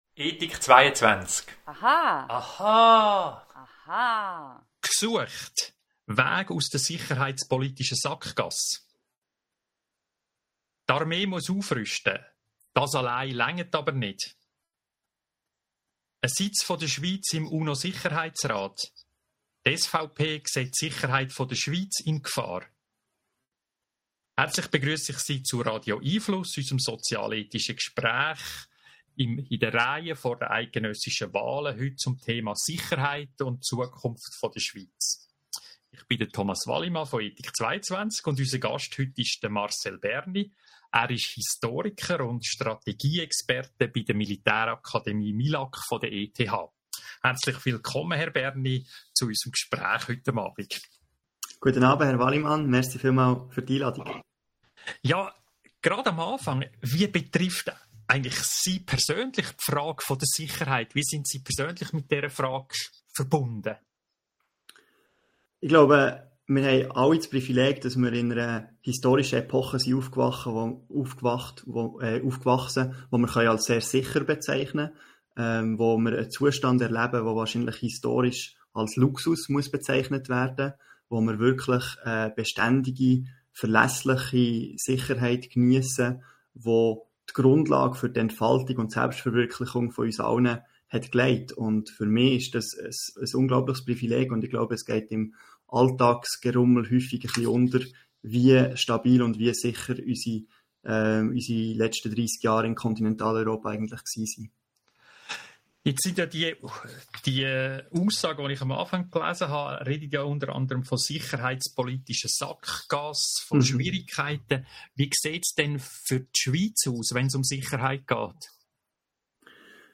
Podcast hier zuhören Radio🎙einFluss findet jeden Mittwochvon 18:30 - 19 Uhr statt .